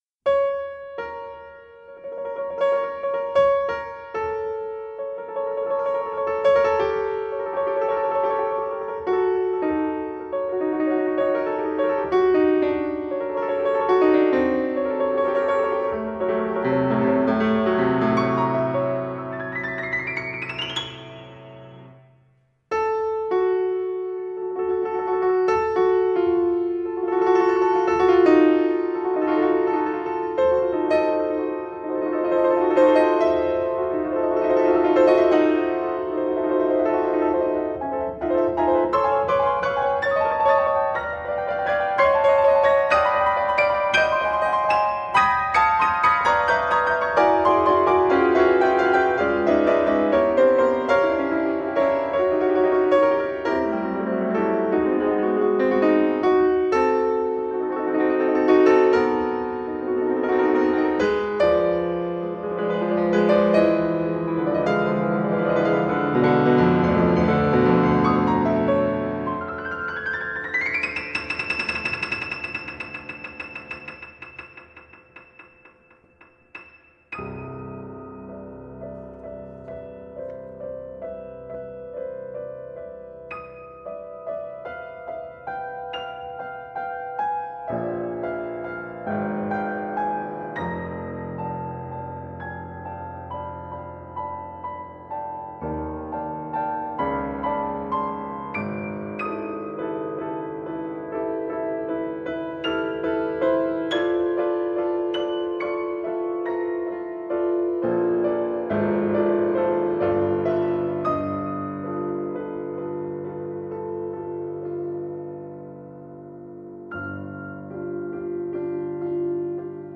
Genre: Piano music
Instrumentation: piano